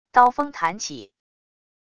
刀锋弹起wav音频